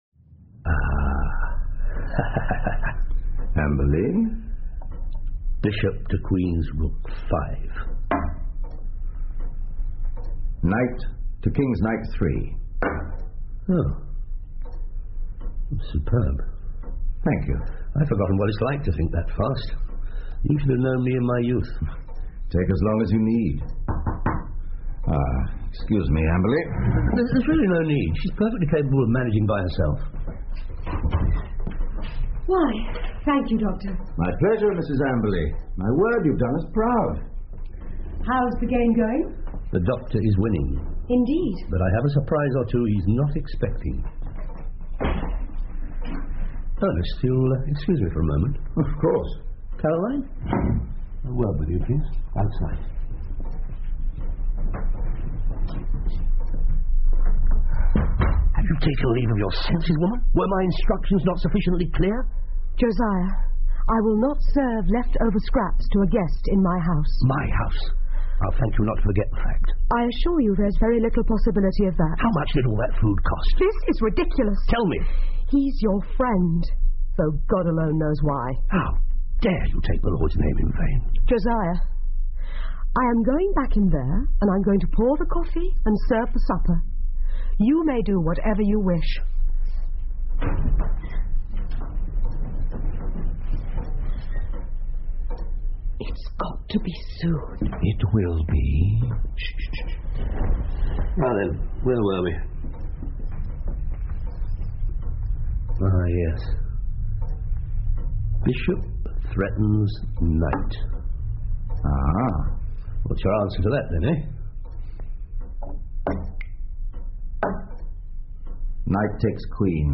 福尔摩斯广播剧 The Retired Colourman 1 听力文件下载—在线英语听力室